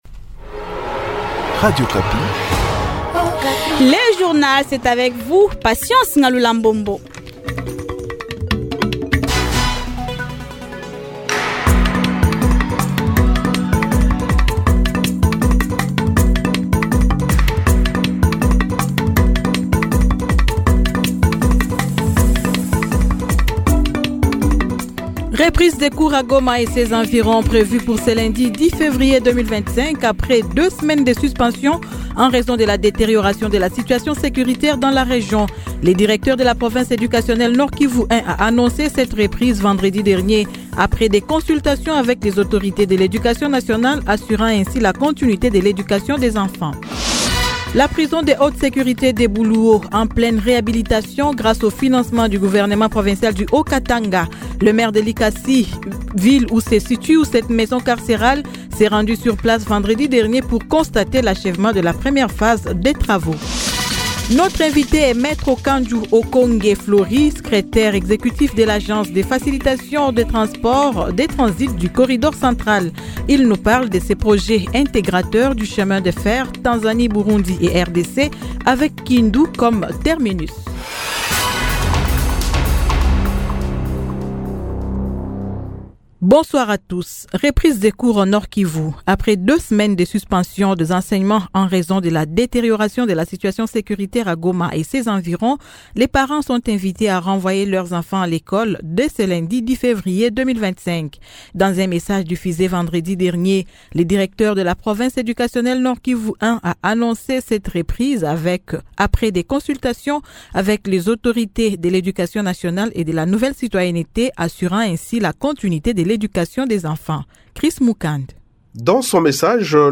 Journal Soir 18h